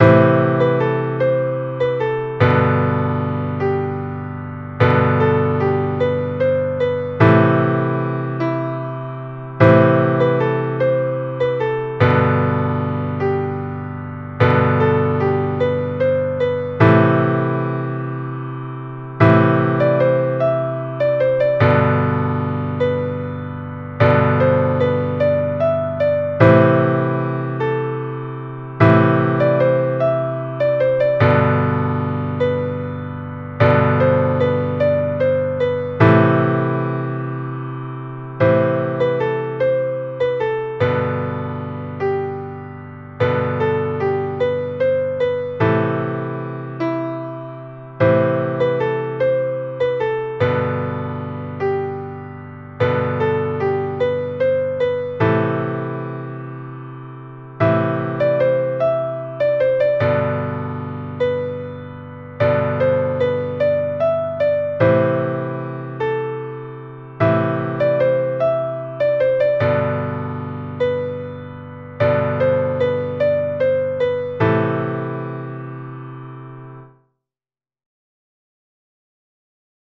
Jewish Folk Song (Shabbat Nachamu, from Isaiah 40:1-2)
Piano Arrangement
A minor ♩= 50 bpm
niggun_008a_nachamu_piano_01.mp3